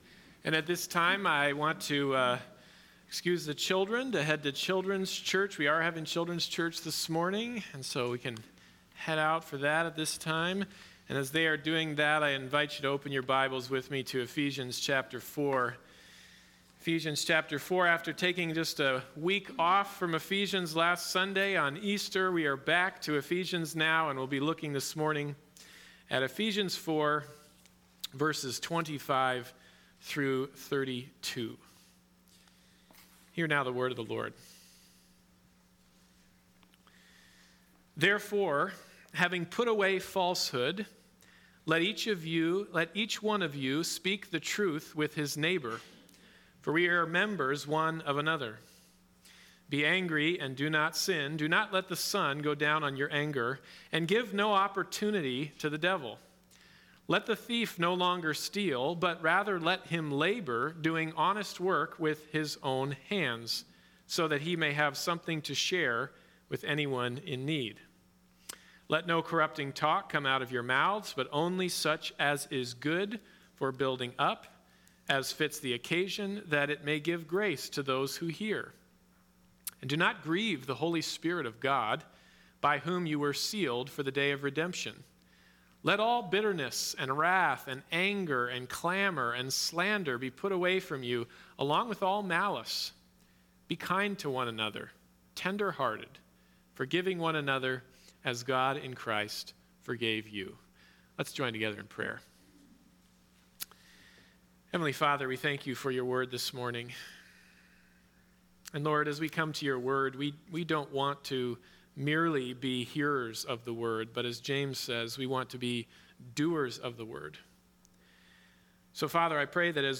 Passage: Ephesians 4:25-32 Service Type: Sunday Morning Service